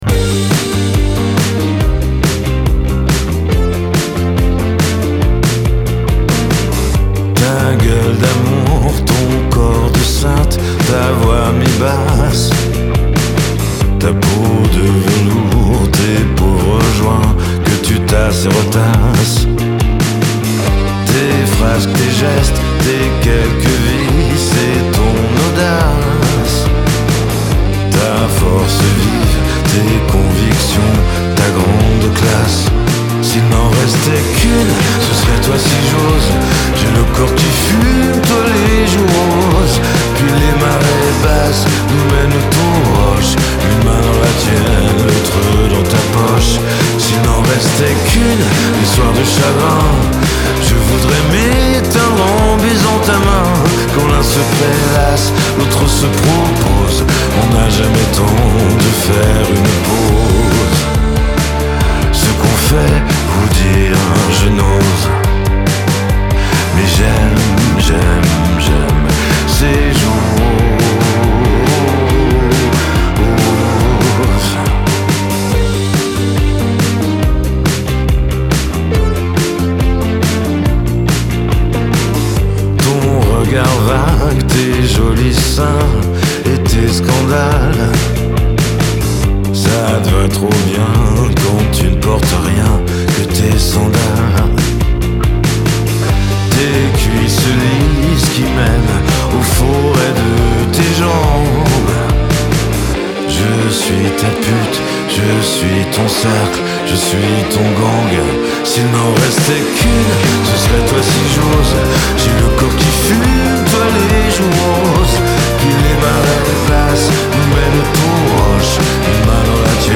Genre : Chanson française